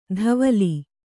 ♪ dhavali